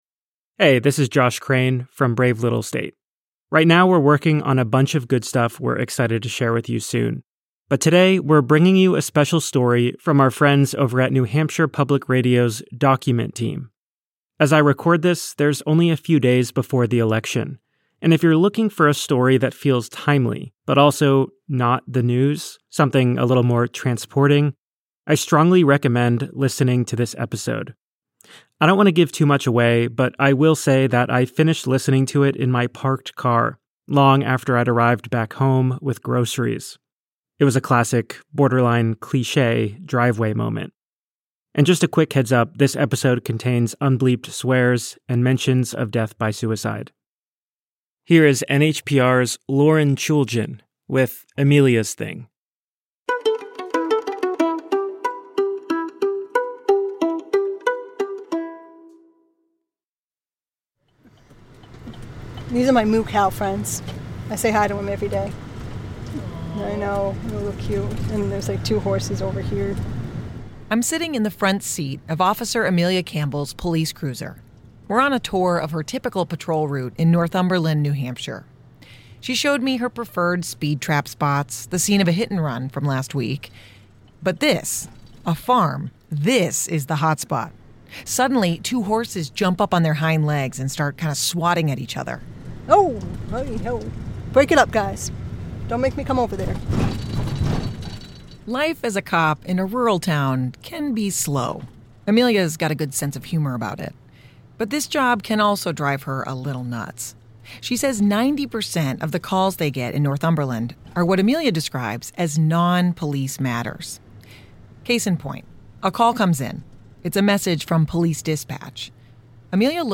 Heads up: This episode contains unbleeped swears and mentions of death by suicide.